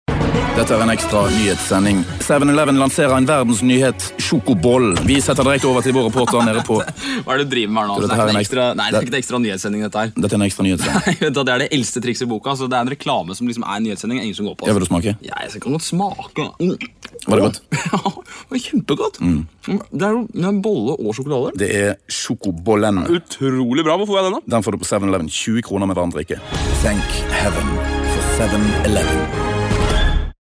Trustworthy, Informative, Versatile - Deep baryton
Sprechprobe: eLearning (Muttersprache):